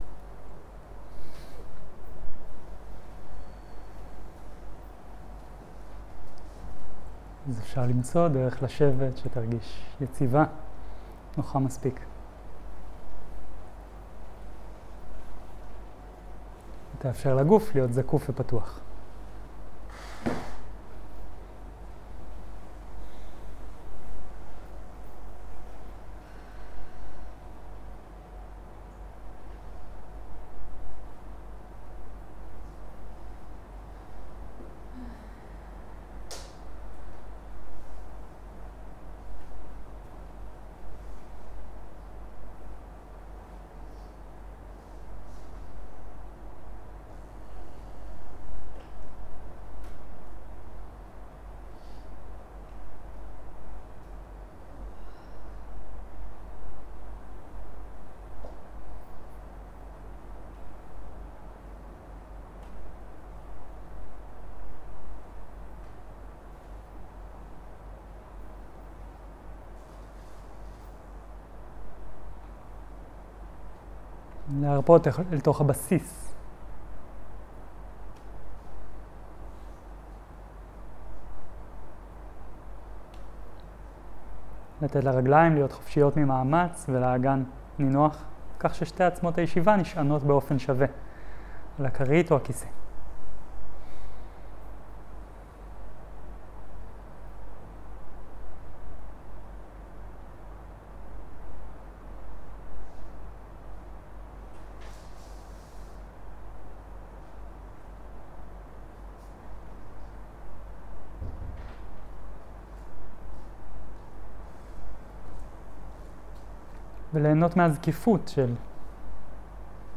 מדיטציה מונחית - עבודה עם כאב
סוג ההקלטה: מדיטציה מונחית